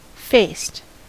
Ääntäminen
Ääntäminen US Tuntematon aksentti: IPA : /ˈfeɪst/ Haettu sana löytyi näillä lähdekielillä: englanti Käännöksiä ei löytynyt valitulle kohdekielelle.